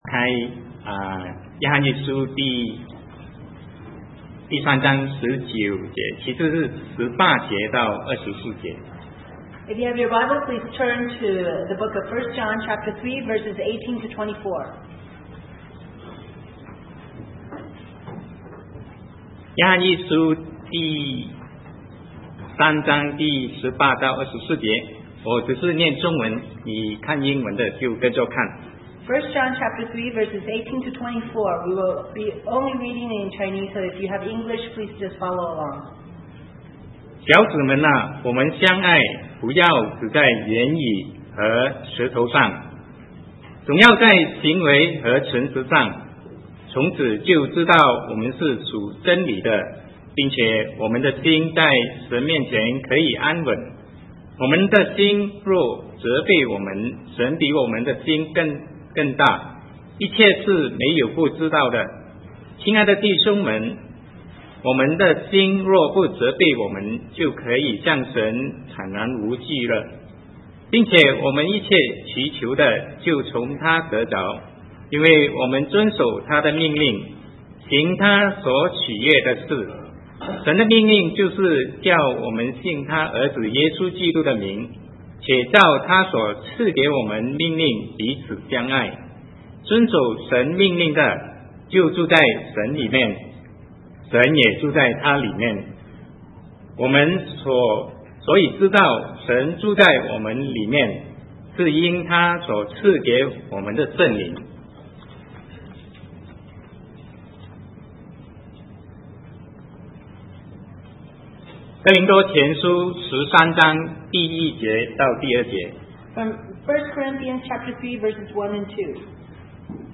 Sermon 2009-12-06 The Assurance of Love